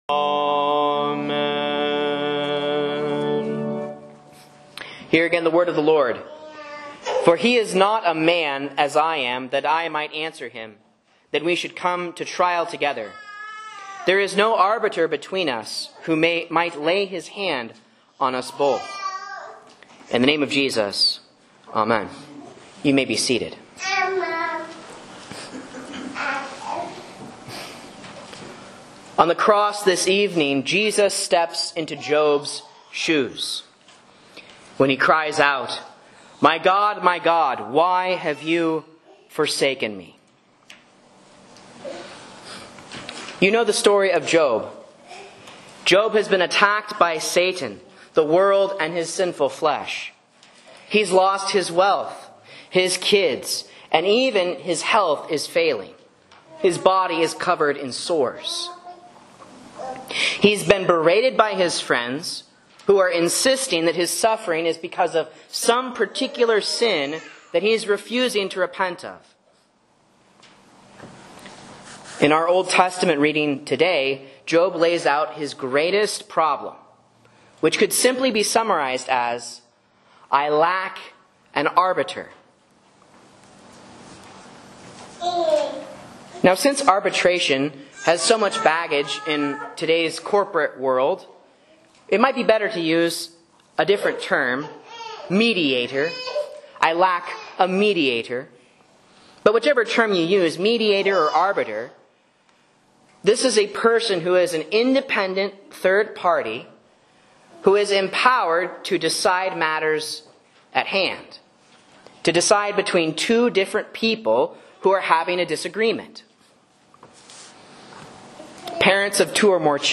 Sermon and Bible Class Audio from Faith Lutheran Church, Rogue River, OR
A Sermon on Job 932-33 & Matthew 27:46 for Lent Midweek